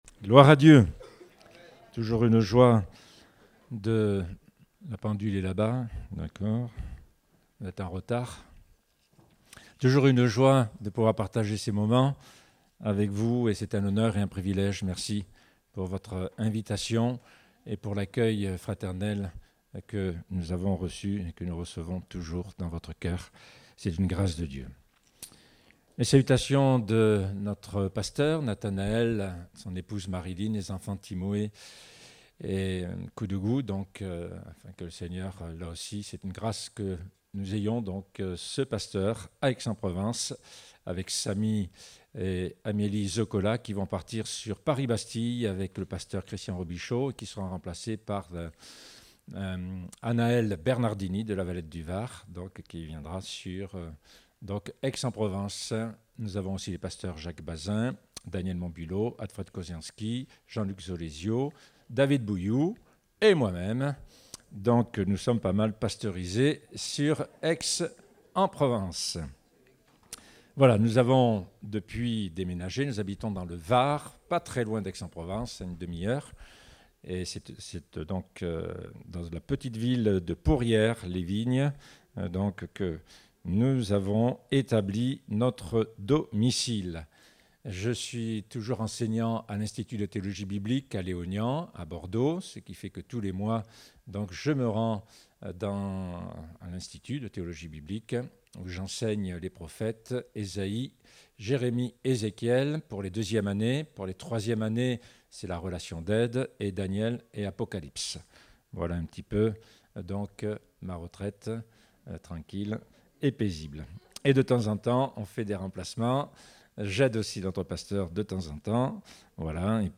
Date : 16 juillet 2023 (Culte Dominical)